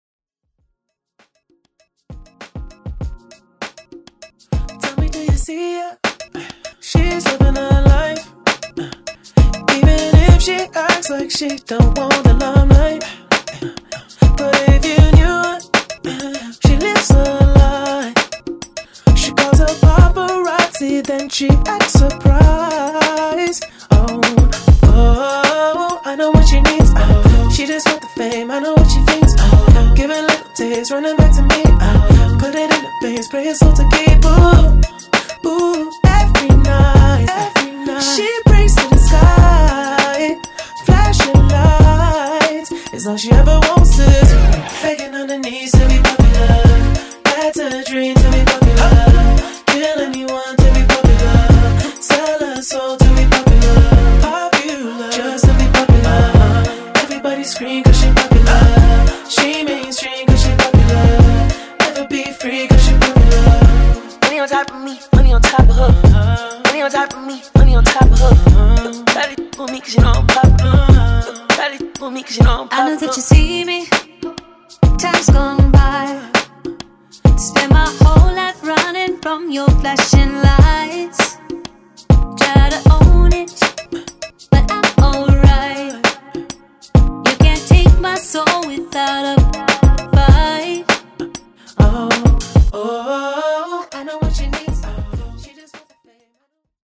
Genres: MASHUPS , TOP40
Clean BPM: 120 Time